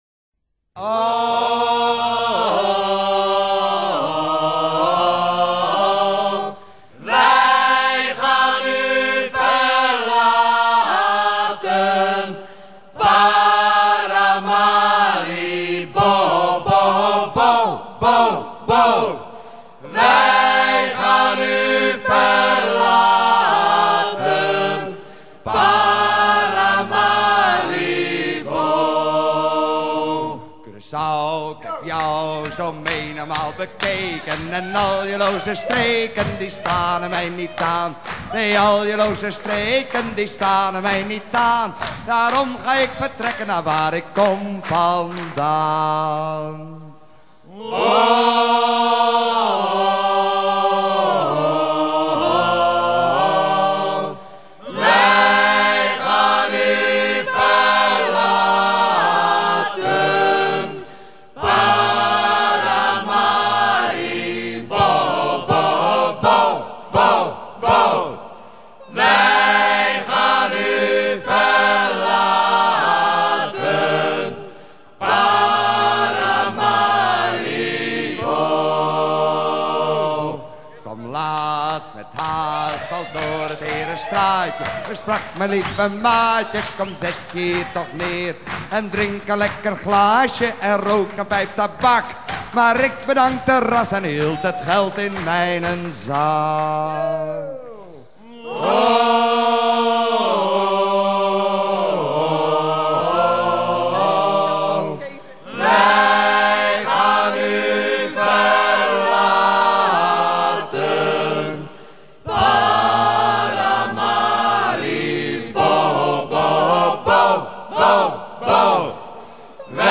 Chanson en frison
enregistrée lors de Brest 92
à virer au cabestan